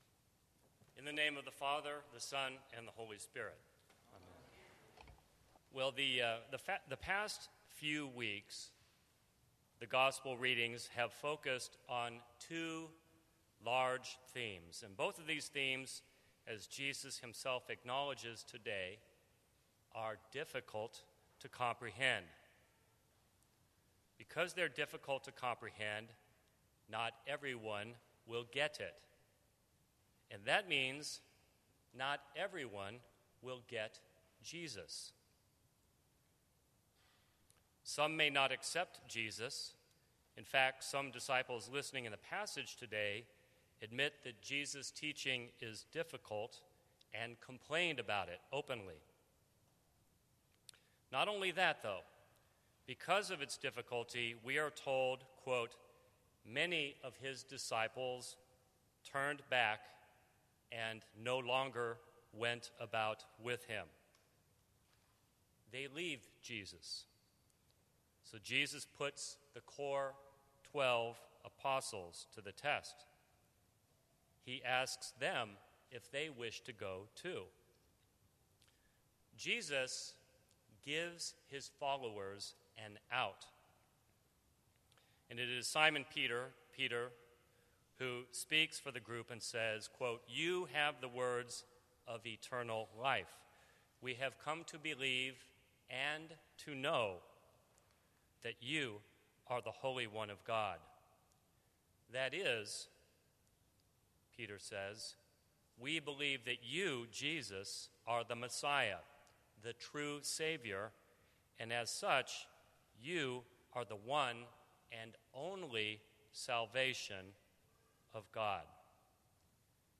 Sermons from St. Cross Episcopal Church Not Everyone ‘Gets Jesus’ Sep 24 2015 | 00:14:27 1x 00:00 / 00:14:27 Subscribe Share Apple Podcasts Spotify Overcast RSS Feed Share Link Embed